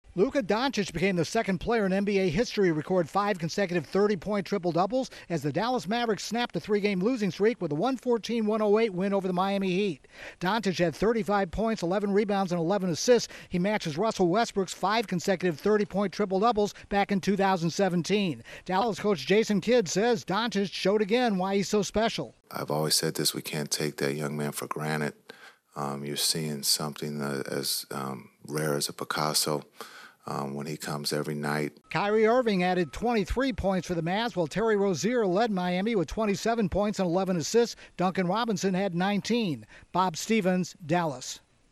Correspondent